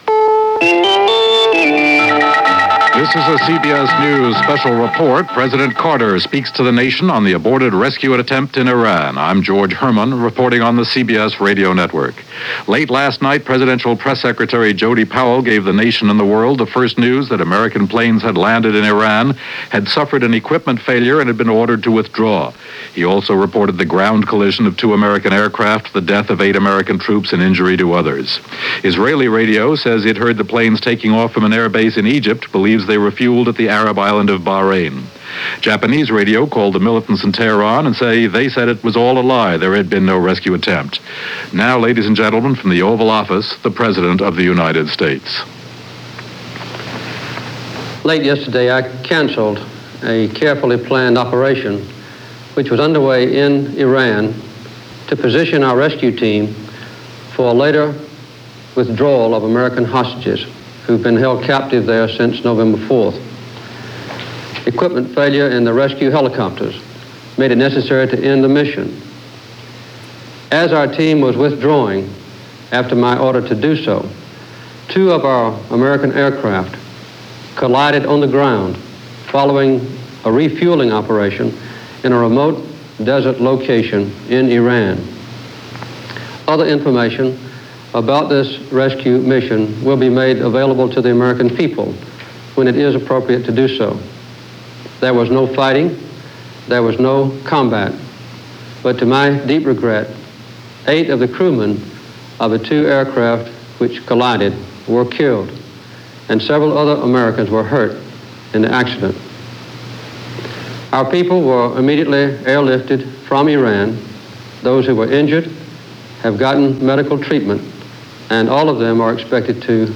The Disaster That Was Operation Eagle Claw - The Hostage Rescue Mission - April 25, 1980 - President Carter Address to nation.